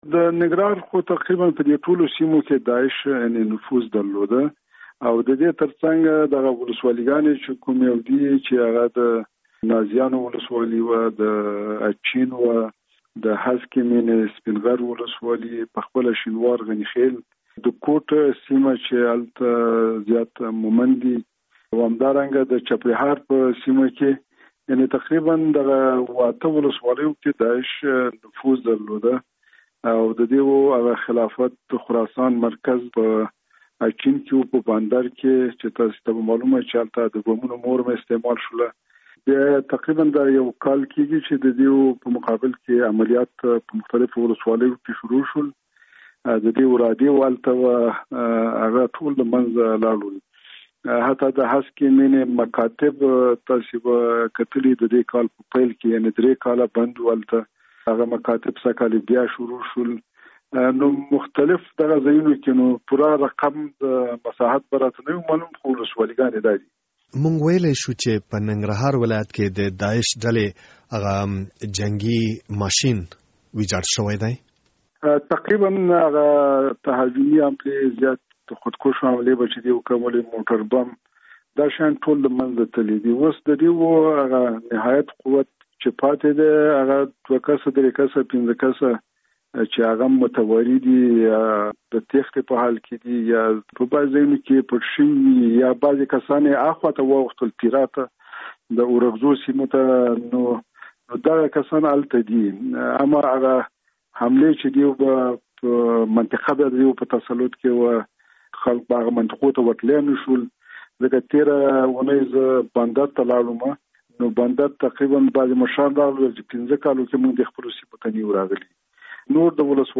مرکه
له ښاغلي میاخېل سره مرکه